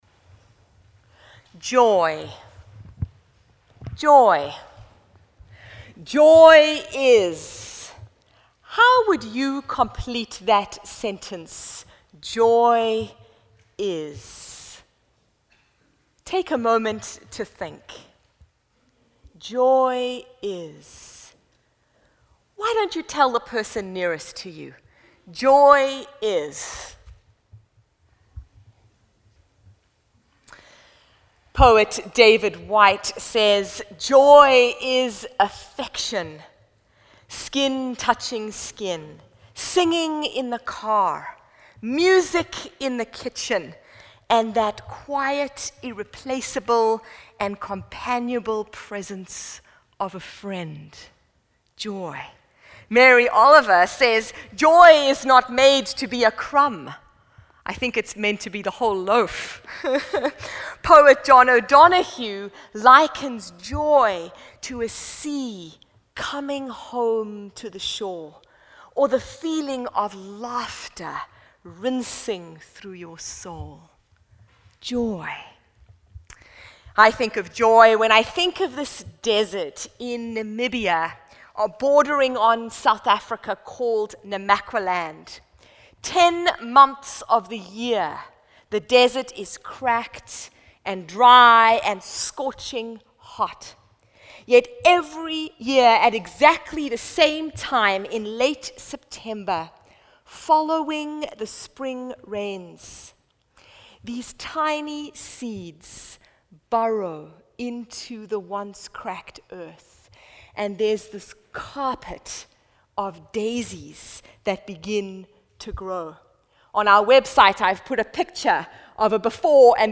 Living the Psalms Current Sermon Present day joy - I dare you!